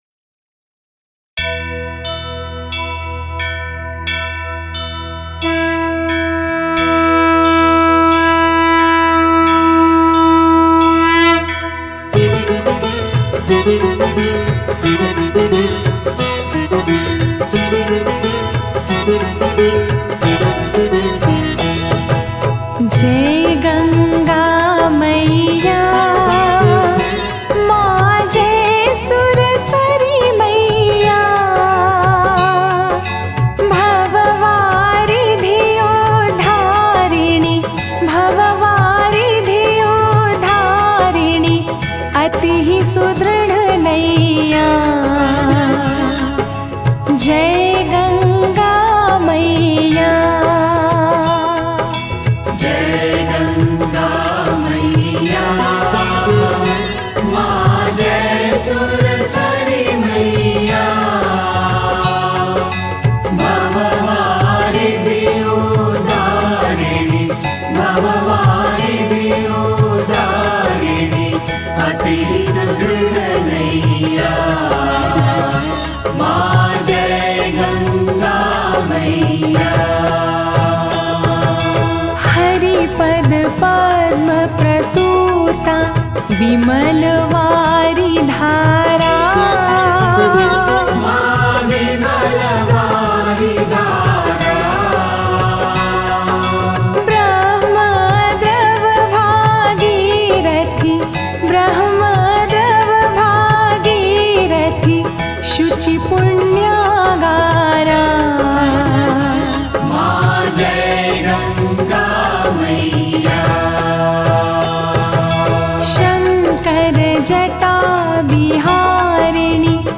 [Devotional]